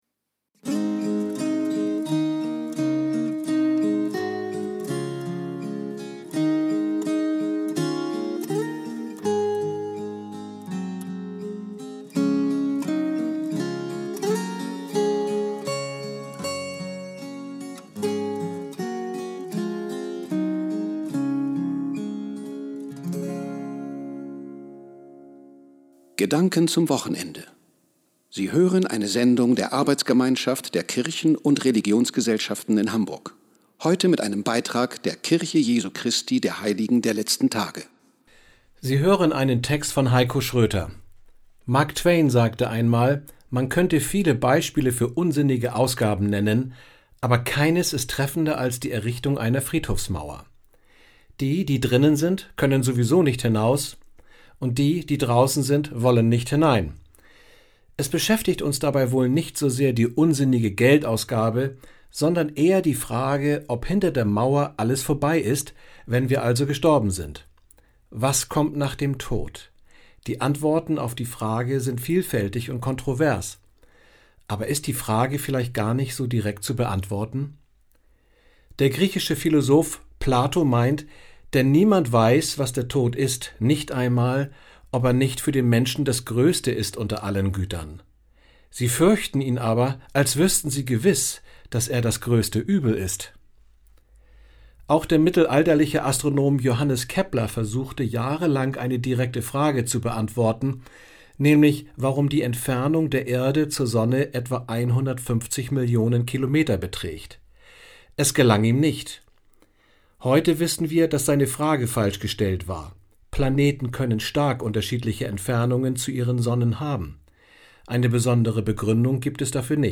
Die am Samstag, den 13. Juni 2015, auf dem Hamburger Bürger- und Ausbildungskanal TIDE gesendete Botschaft der Kirche Jesu Christi der Heiligen der Letzten Tage zum Thema "Ist das Leben endlich?" steht ab sofort als Podcast auf der Presseseite zur Verfügung.